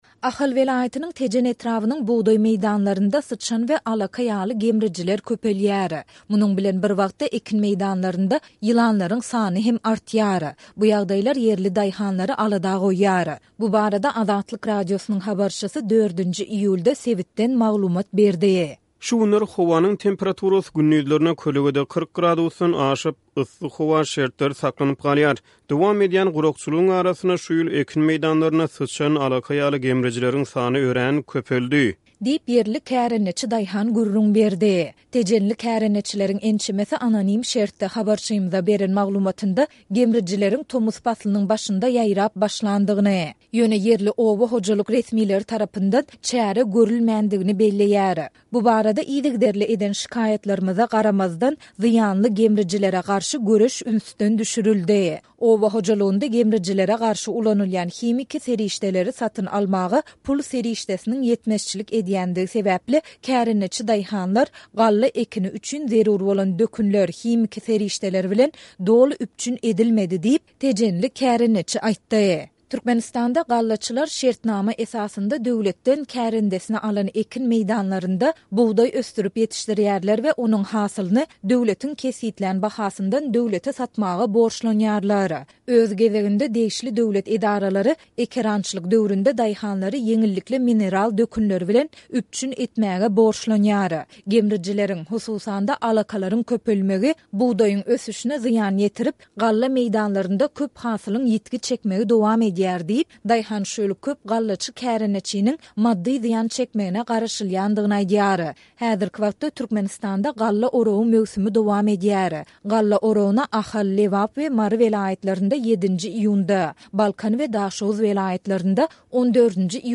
Bu barada Azatlyk Radiosynyň habarçysy 4-nji iýulda sebitden maglumat berdi.